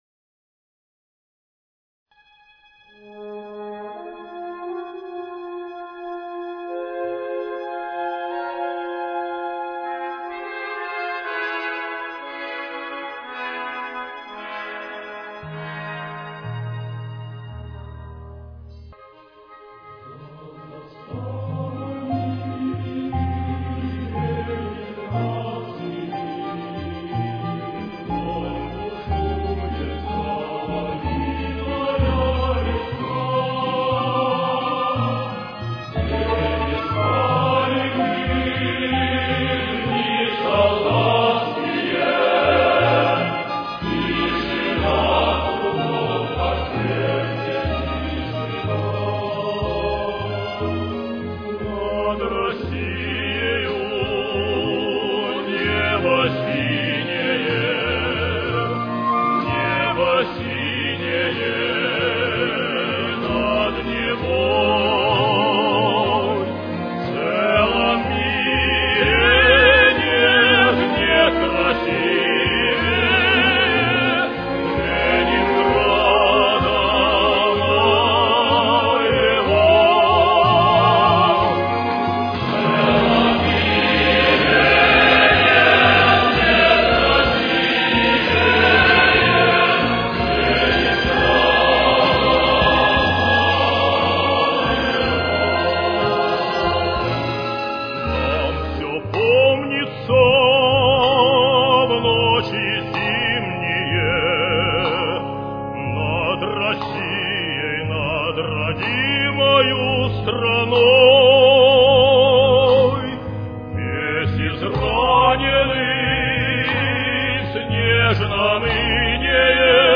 Ля-бемоль мажор. Темп: 64.